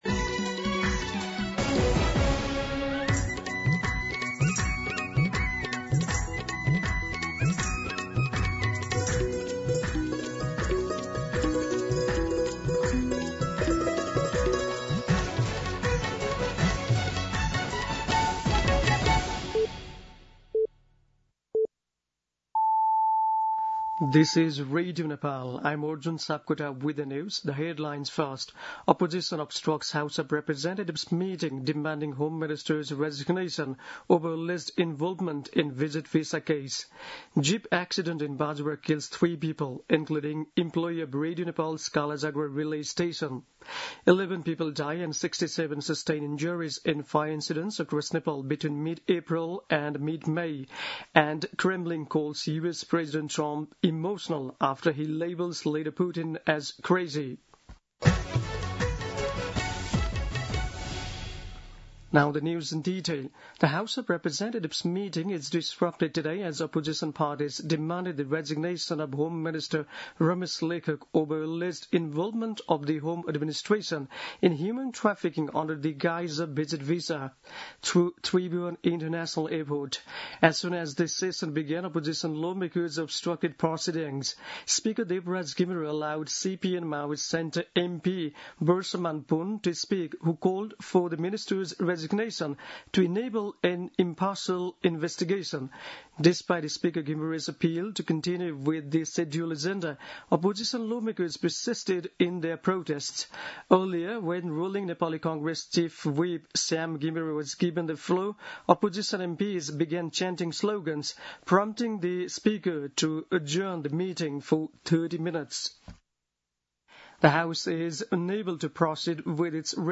दिउँसो २ बजेको अङ्ग्रेजी समाचार : १३ जेठ , २०८२
2pm-English-News-13.mp3